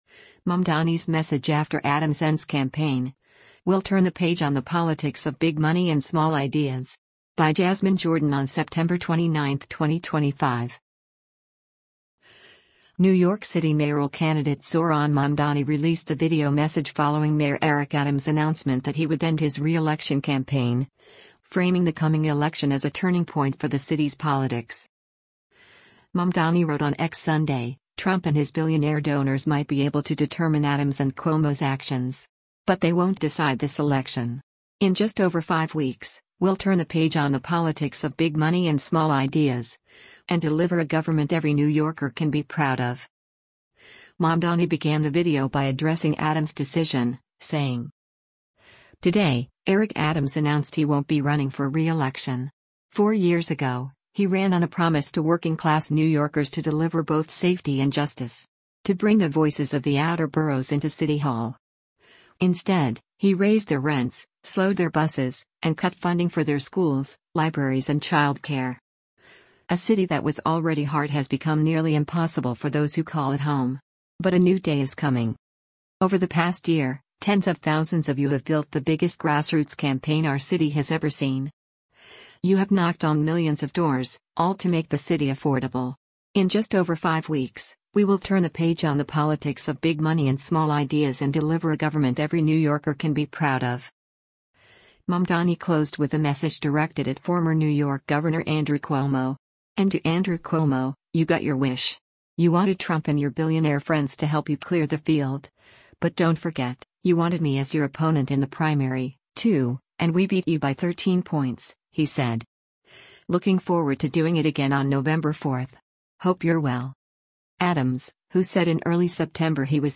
New York City mayoral candidate Zohran Mamdani released a video message following Mayor Eric Adams’ announcement that he would end his reelection campaign, framing the coming election as a turning point for the city’s politics.